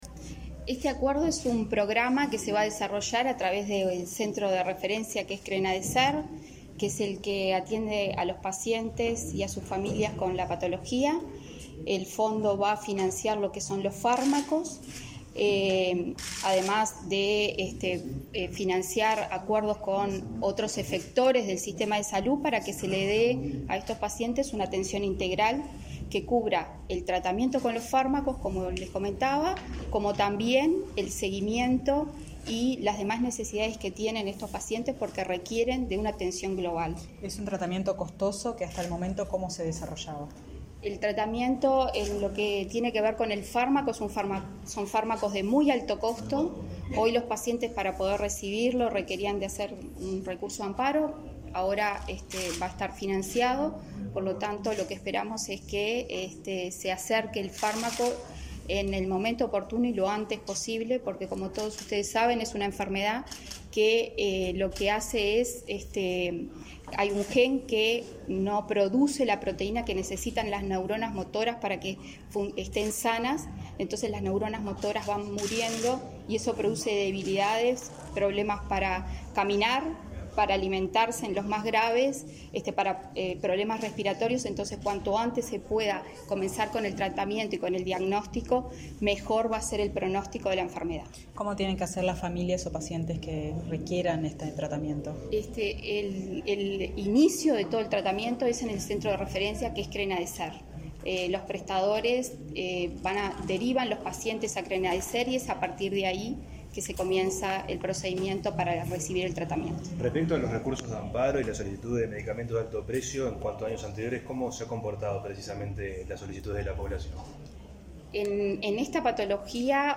Declaraciones de la presidenta del Fondo Nacional de Recursos
Declaraciones de la presidenta del Fondo Nacional de Recursos 23/08/2022 Compartir Facebook X Copiar enlace WhatsApp LinkedIn La presidenta del Fondo Nacional de Recursos (FNR), Ana María Porcelli, dialogó con la prensa, luego de la conferencia en la que se informó sobre un nuevo programa de tratamiento para pacientes con atrofia muscular espinal.